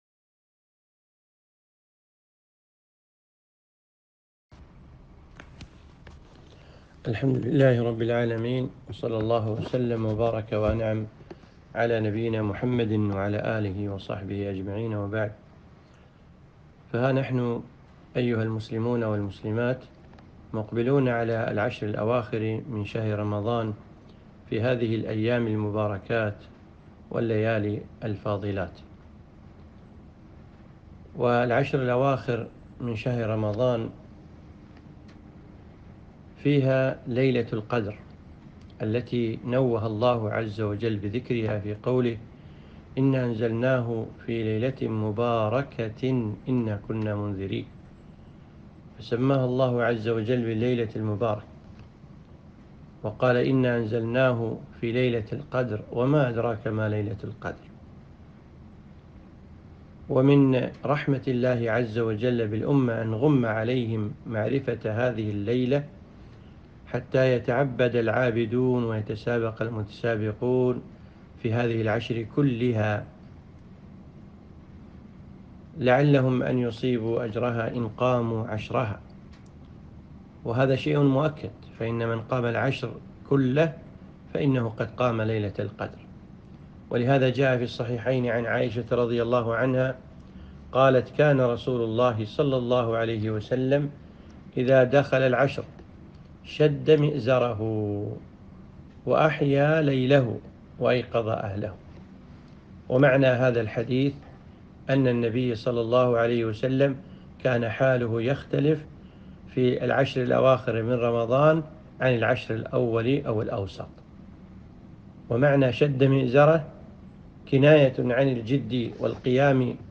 كلمة - وأقبلت العشر